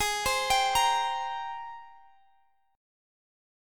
Listen to G#M9 strummed